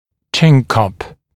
[ʧɪn kʌp][чин кап]подчелюстная (подбородочная) праща